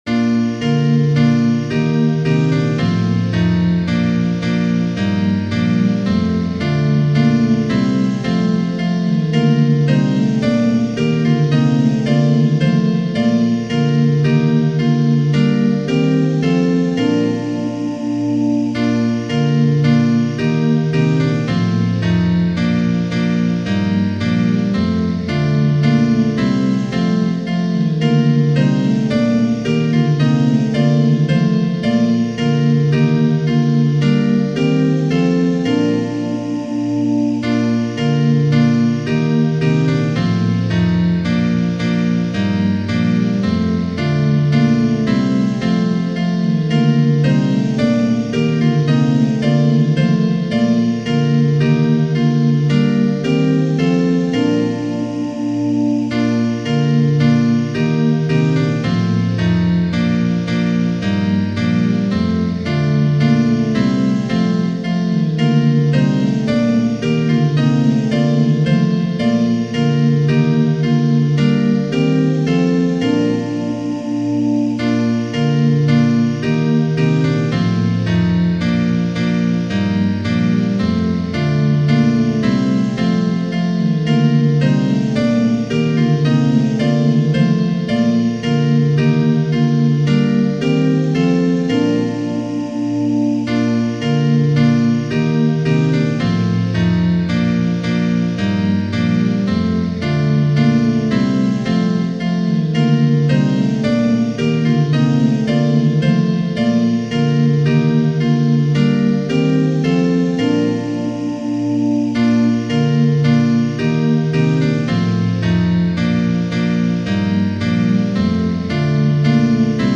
LXXXV. HYMN.—p.m.
Hymnbooks from his time contained words, but not tunes – people sang the hymns to one of the tunes they knew that fit the meter, and the tune we used was Winchester New, which was more than a hundred years old back then, but is still being used.
2-winchester_new-intro8.mp3